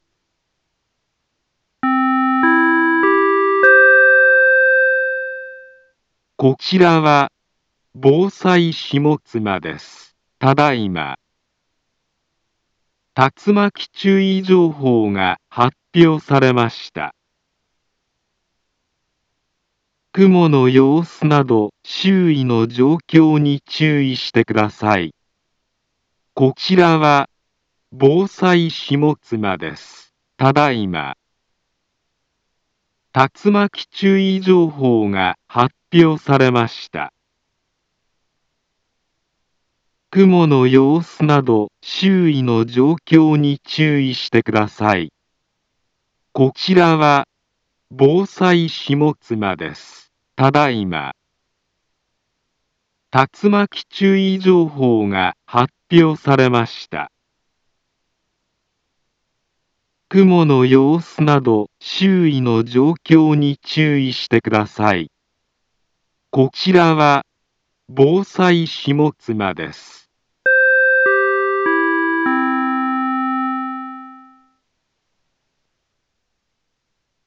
Back Home Ｊアラート情報 音声放送 再生 災害情報 カテゴリ：J-ALERT 登録日時：2022-06-27 18:35:07 インフォメーション：茨城県北部、南部は、竜巻などの激しい突風が発生しやすい気象状況になっています。